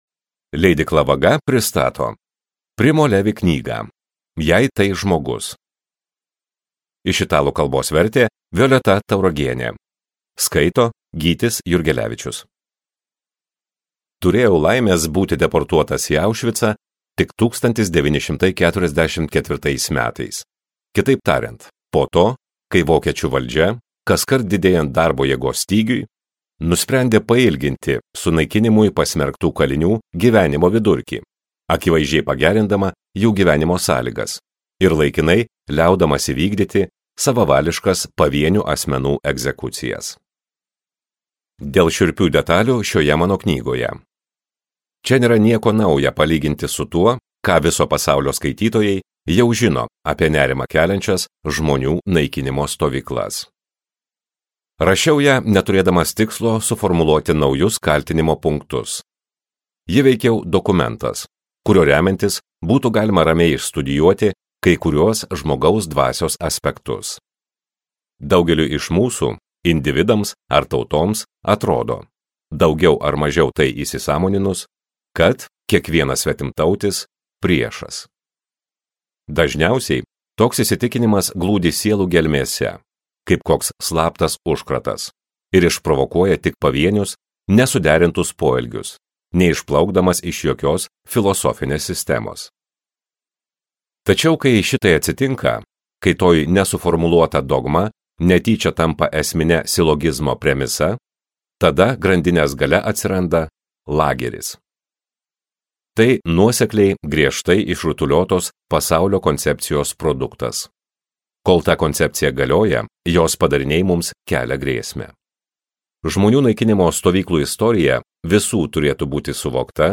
Jei tai žmogus | Audioknygos | baltos lankos